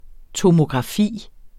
Udtale [ tomogʁɑˈfiˀ ]